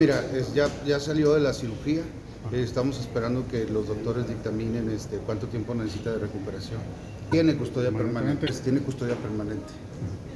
Así lo dio a conocer el titular de la FGE, Roberto Fierro Duarte, entrevistado por medios de comunicación.
Fiscal-general-sobre-Cesar-Duarte.mp3